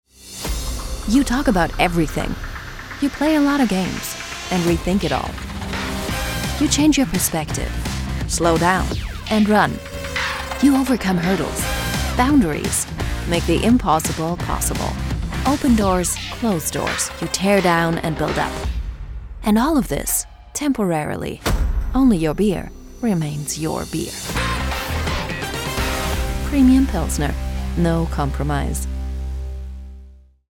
Female
Assured, Authoritative, Character, Confident, Corporate, Engaging, Friendly, Natural, Smooth, Soft, Warm, Versatile
German, Ruhrpott (native)
Microphone: Sennheiser MKH 416